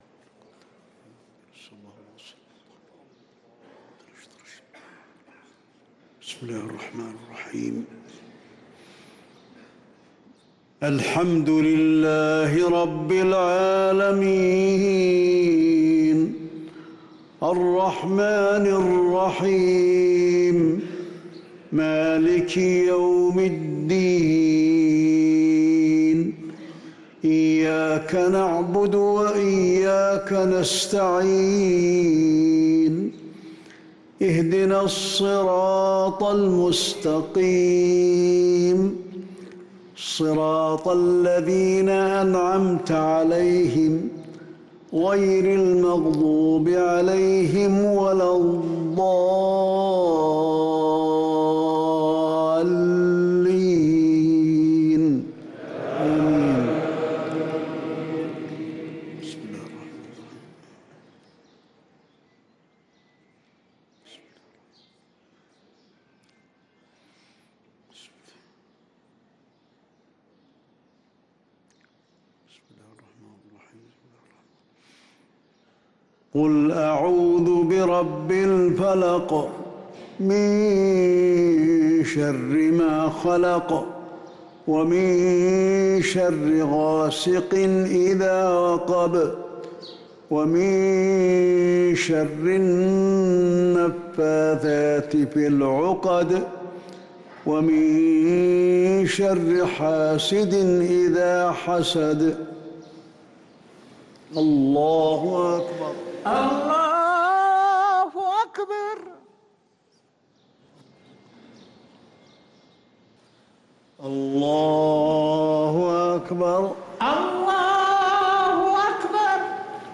صلاة المغرب للقارئ علي الحذيفي 27 رجب 1445 هـ